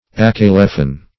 Search Result for " acalephan" : The Collaborative International Dictionary of English v.0.48: Acaleph \Ac"a*leph\, Acalephan \Ac`a*le"phan\n.; pl.